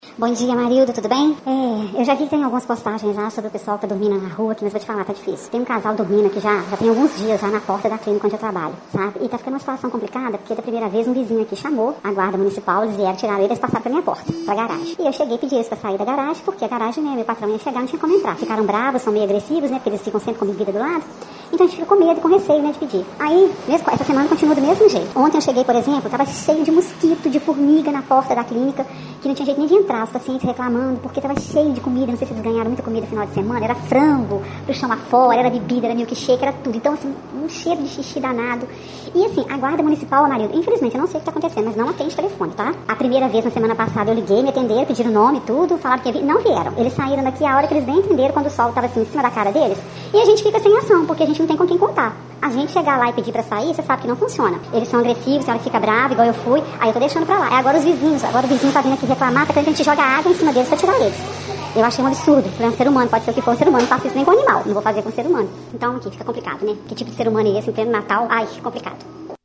**Por motivo de segurança, foi utilizado efeito pith comp. na voz da reclamante.